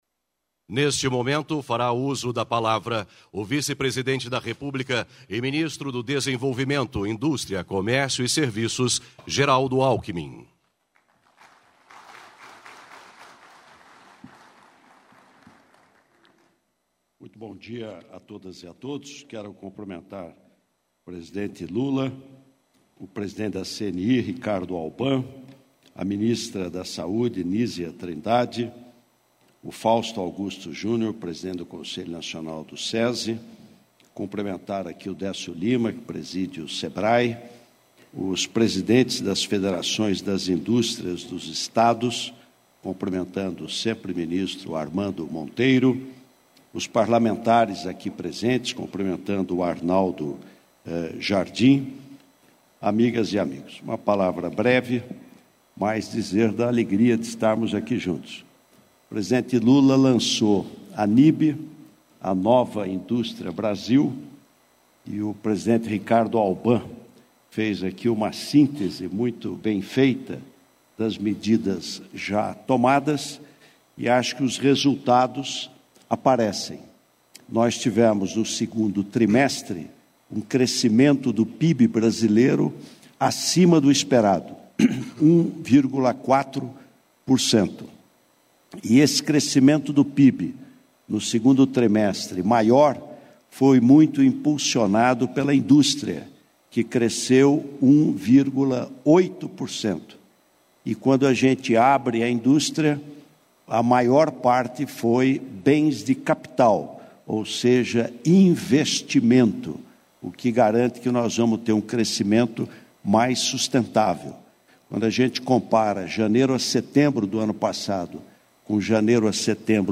Íntegra do discurso do vice-presidente e ministro do Desenvolvimento, Indústria, Comércio e Serviços, Geraldo Alckmin, no evento, sediado no Centro Internacional de Convenções do Brasil, em Brasília, nesta quarta-feira (27).